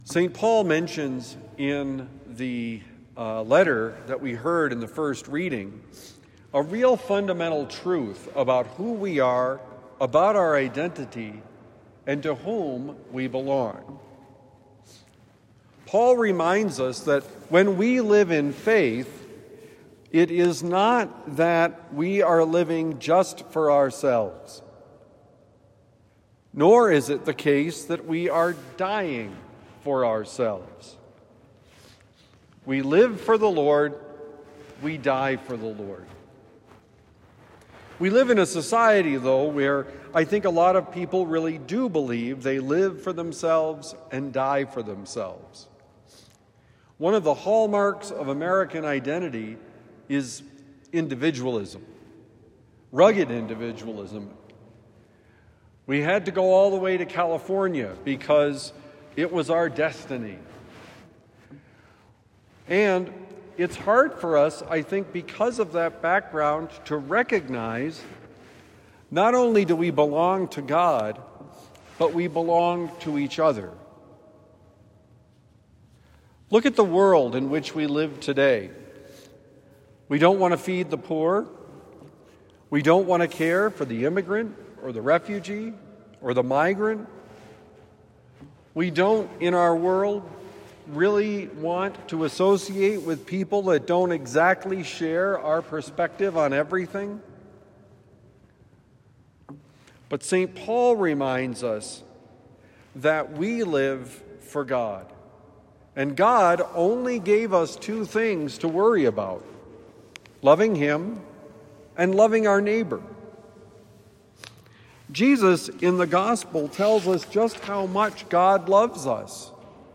We belong to Jesus: Homily for Thursday, November 6, 2025